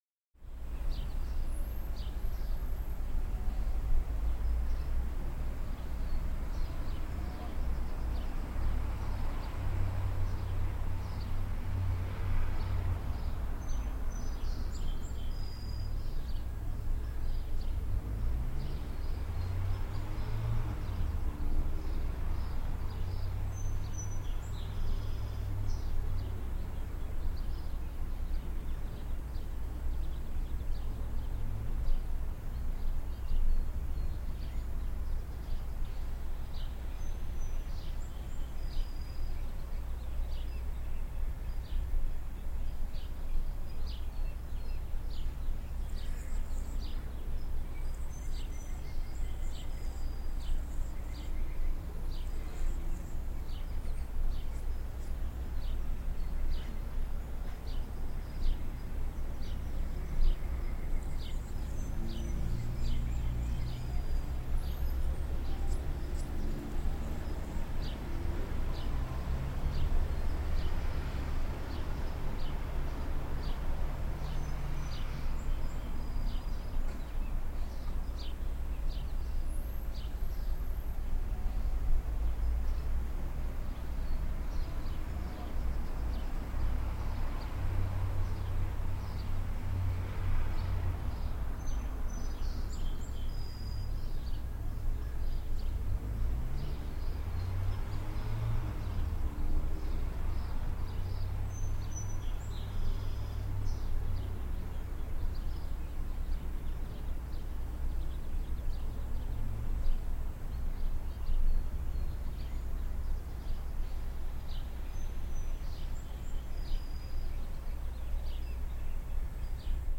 На этой странице собраны звуки, наполненные теплом и светом солнечного дня: пение птиц, шелест листвы, детский смех на улице.
Звуки солнечного летнего дня